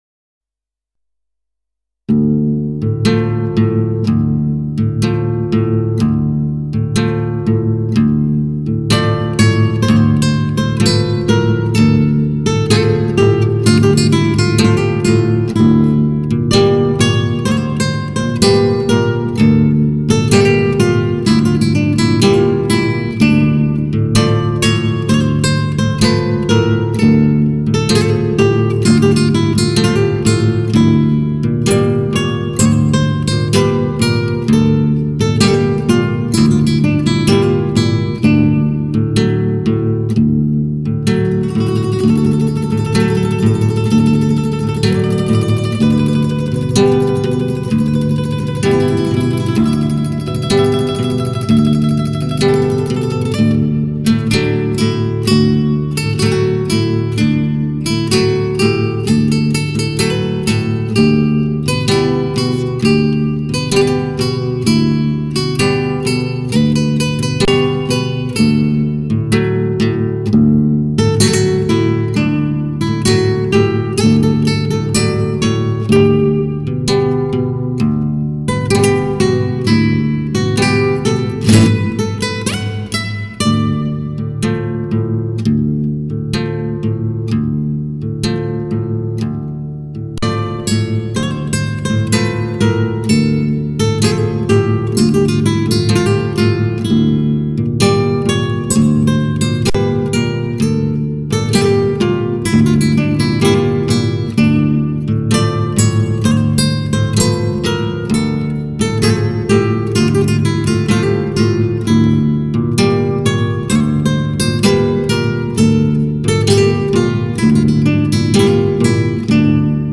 流派：      Classic Music